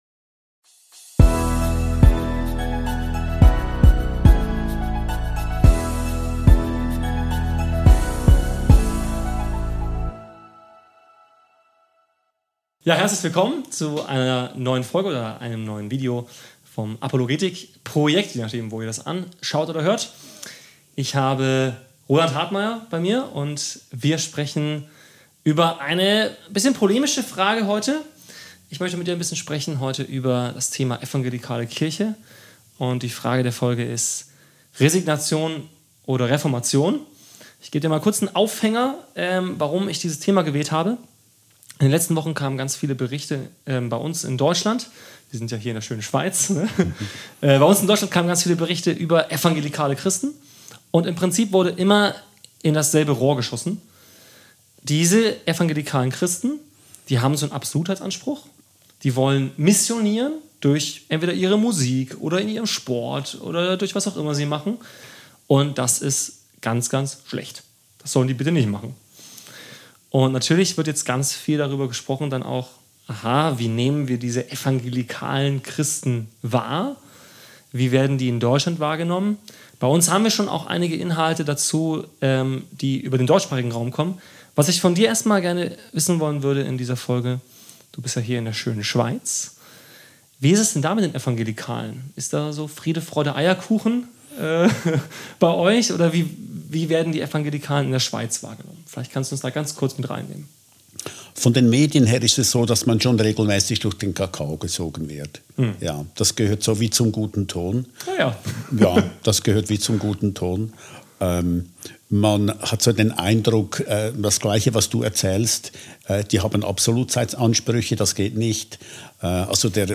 Ein Gespräch darüber, wie Christen heute glaubwürdig und wirksam leben können.